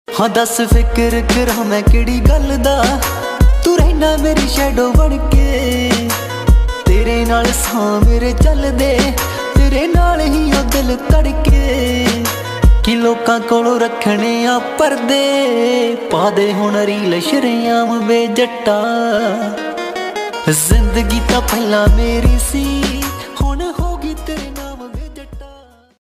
Latest punjabi song ringtone 2023